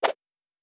Whoosh 10_14.wav